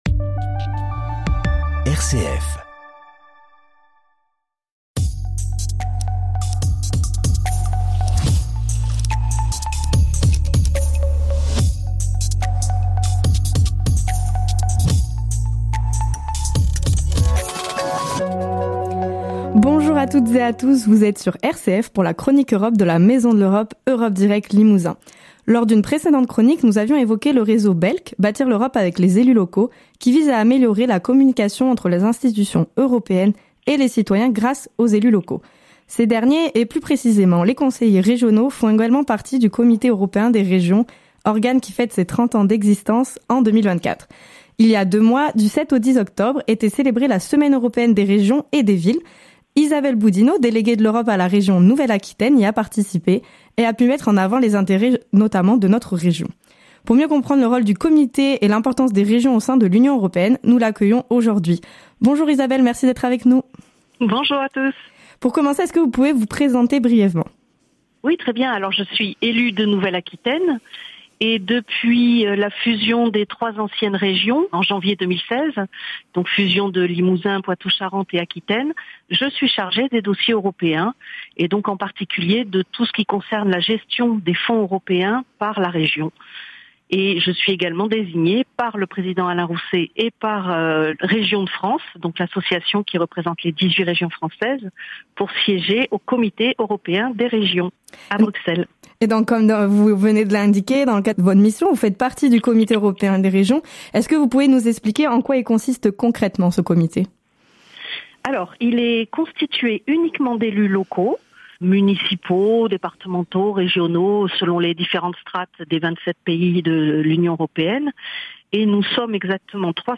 Isabelle BOUDINEAU, déléguée à l’Europe de la Région Nouvelle-Aquitaine y a participé et a pu mettre en avant les intérêts de notre région. Pour mieux comprendre le rôle du Comité et l’importance des régions au sein de l’UE, nous l’accueillons aujourd’hui.